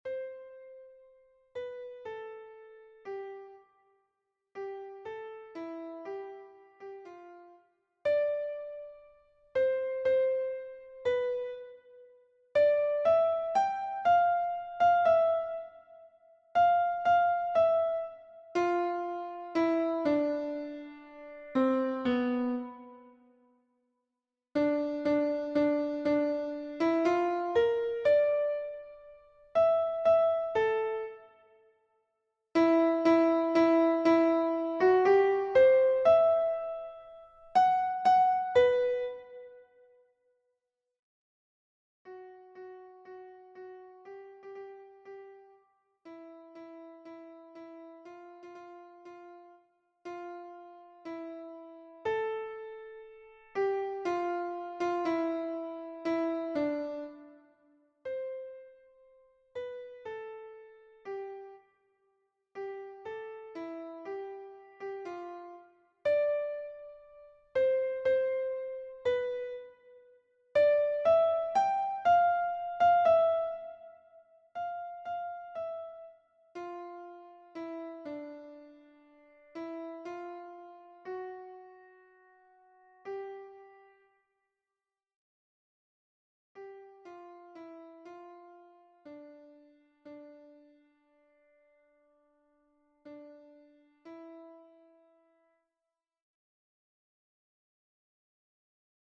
mscz pdf soprán